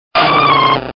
Archivo:Grito de Delibird.ogg